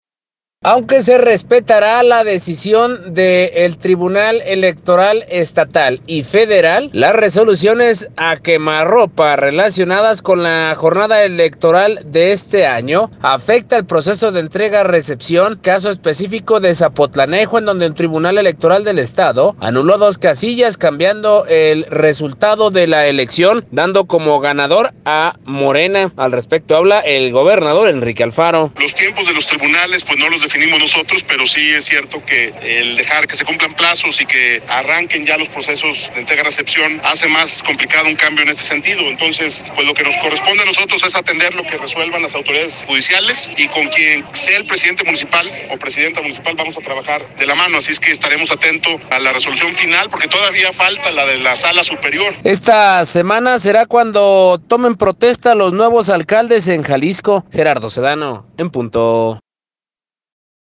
Al respecto habla el gobernador Enrique Alfaro: